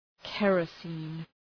{‘kerə,si:n}
kerosene.mp3